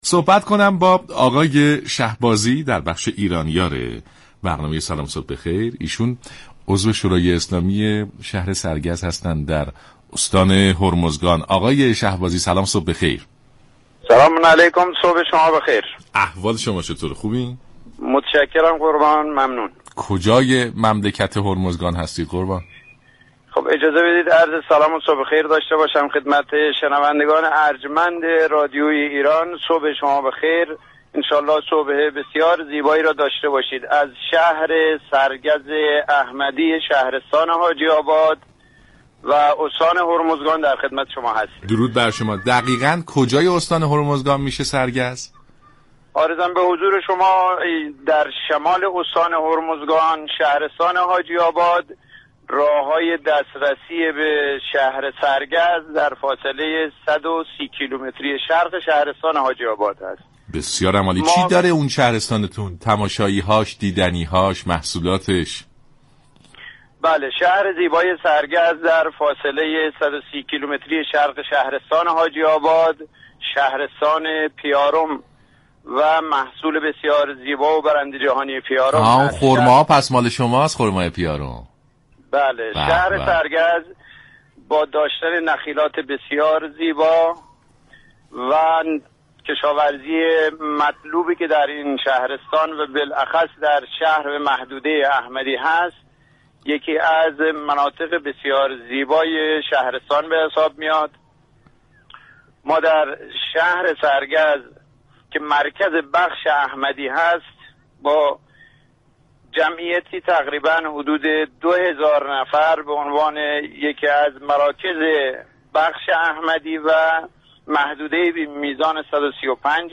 شهبازی عضو شورای اسلامی شهر سرگز در استان هرمزگان در بخش ایرانیار برنامه سلام صبح بخیر